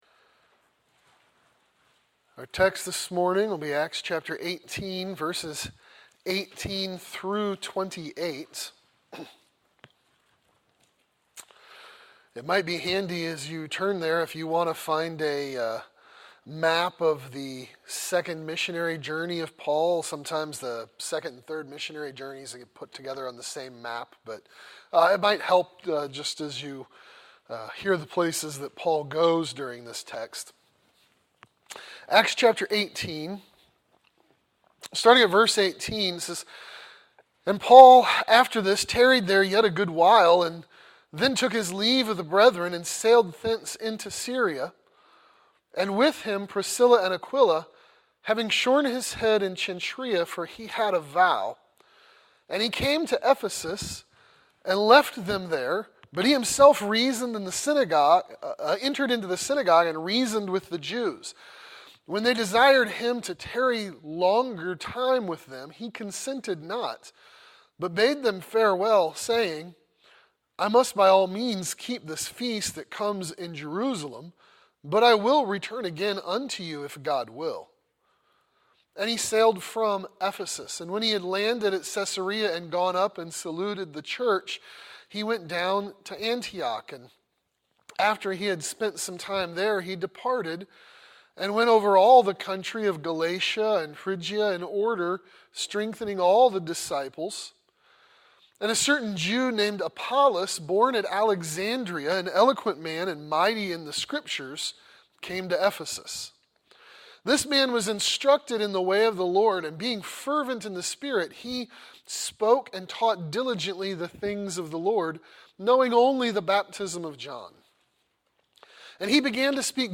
Workers Growing In Grace | SermonAudio Broadcaster is Live View the Live Stream Share this sermon Disabled by adblocker Copy URL Copied!